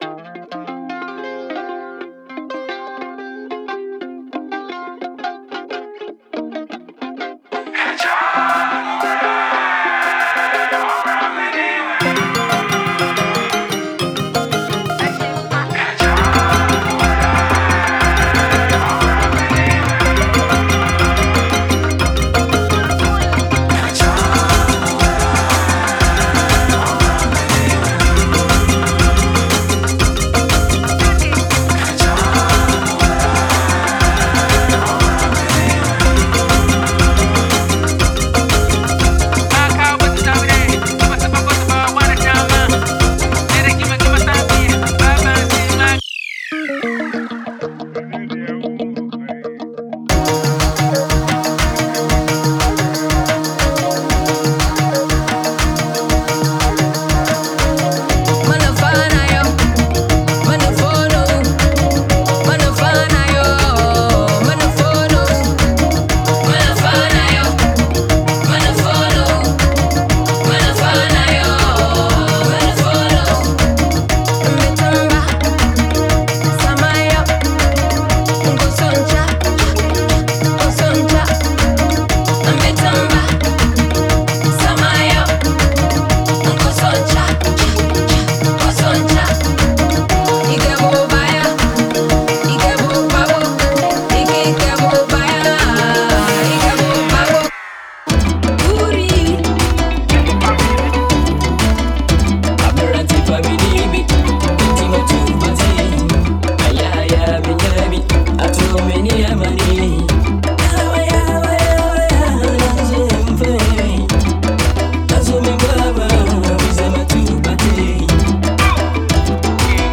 Genre:Africa
コラ、ンゴニ、カラバッシュ、アフリカンシロフォン、サラム、フルート、バラフォンなどを用いて巧みに制作されています。
高揚感のあるフック、リズミカルなギターリック、催眠的なパーカッション